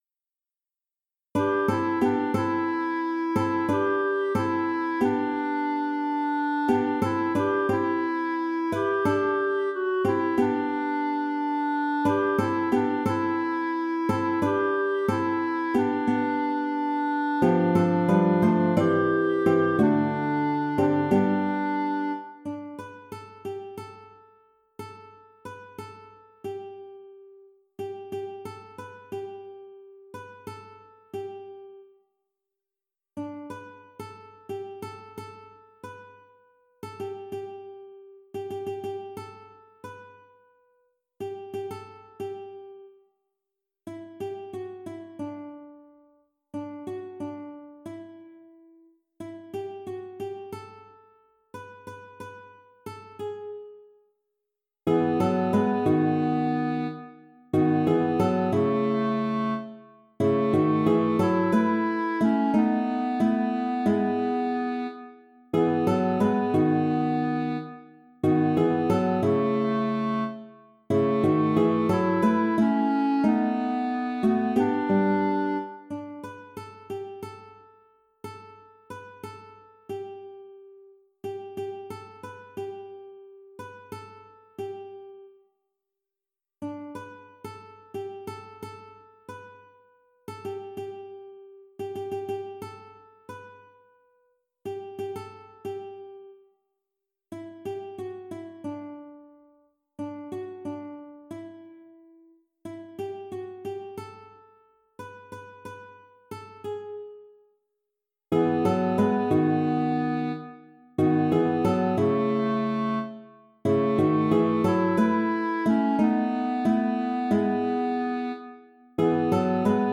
Vart du än går alt